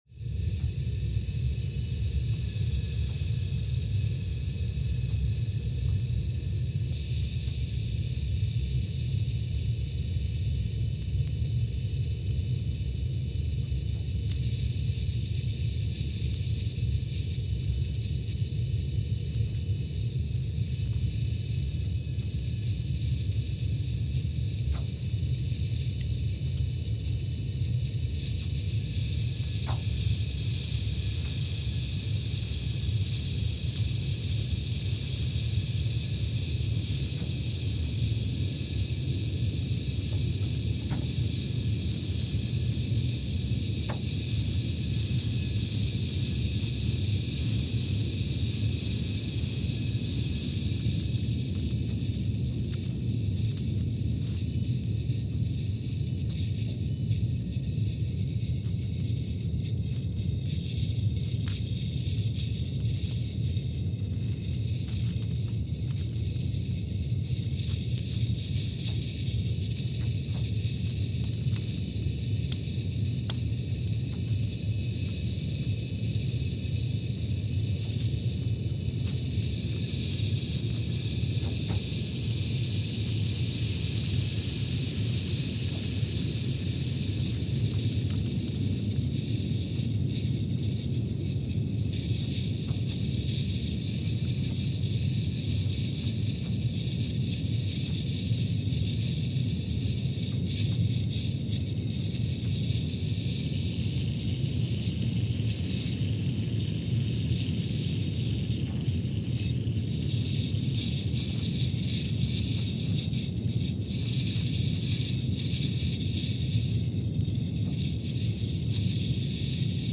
Scott Base, Antarctica (seismic) archived on May 30, 2022
Station : SBA (network: IRIS/USGS) at Scott Base, Antarctica
Sensor : CMG3-T
Speedup : ×500 (transposed up about 9 octaves)
Gain correction : 25dB
SoX post-processing : highpass -2 90 highpass -2 90